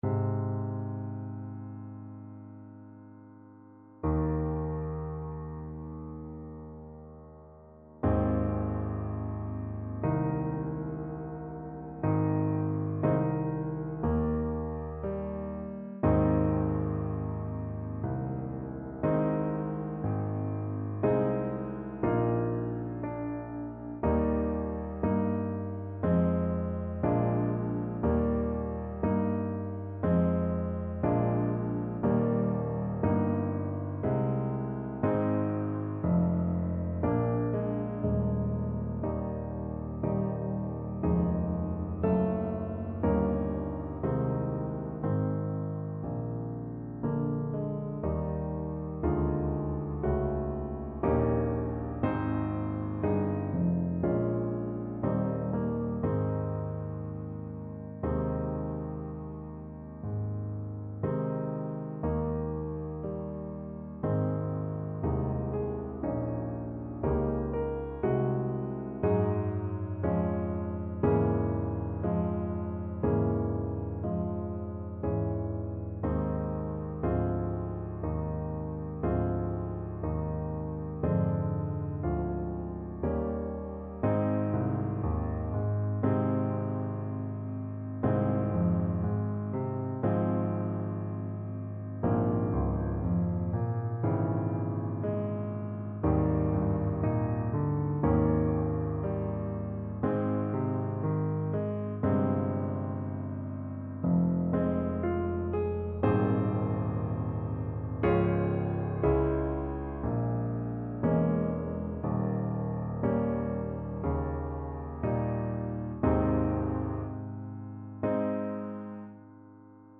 Play (or use space bar on your keyboard) Pause Music Playalong - Piano Accompaniment transpose reset tempo print settings full screen
2/4 (View more 2/4 Music)
Ab major (Sounding Pitch) F major (Alto Saxophone in Eb) (View more Ab major Music for Saxophone )
~ = 100 Adagio =c.60
Classical (View more Classical Saxophone Music)